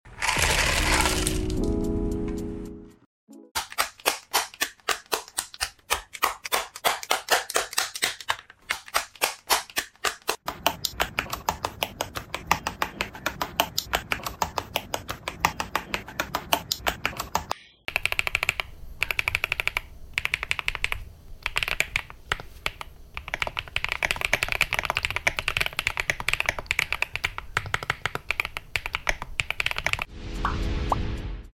Sound test Switch MMD Princess sound effects free download
Sound test Switch MMD Princess V4 Tactile